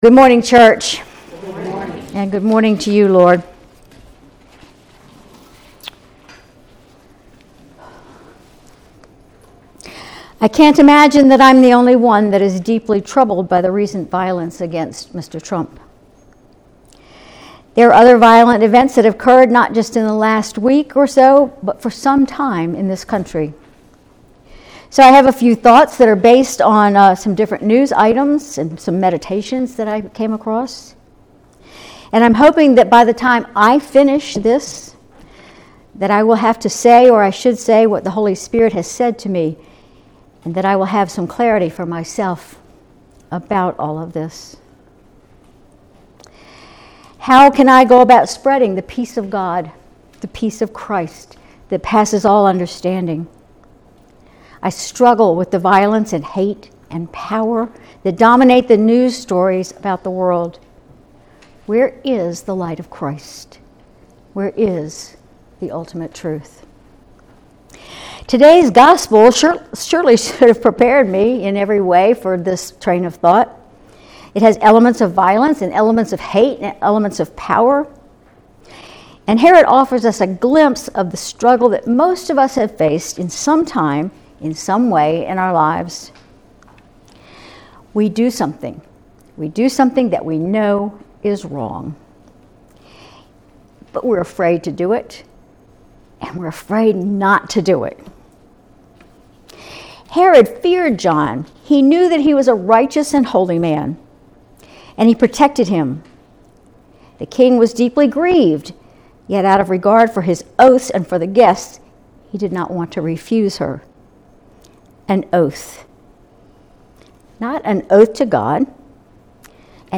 Sermon July 14, 2024